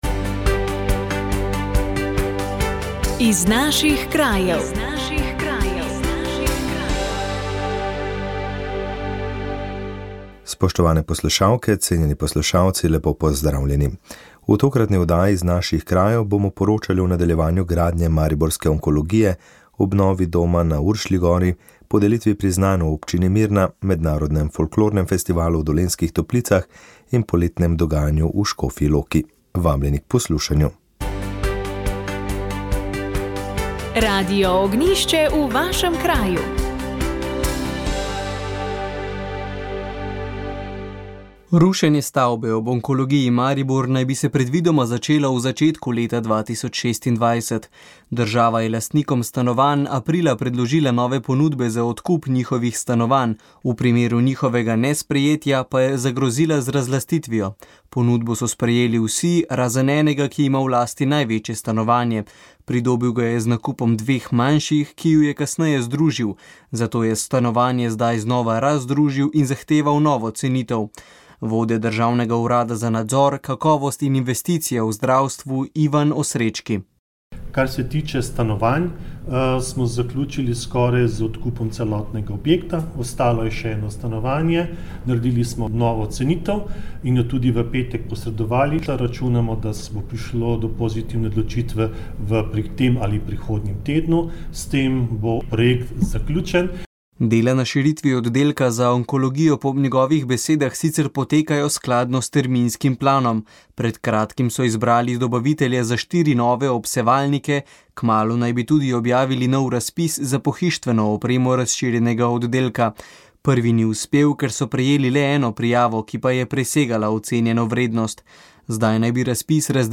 V oddaji Moja zgodba lahko poslušate pričevanje o časih po drugi svetovni vojni s strani naslednje generacije.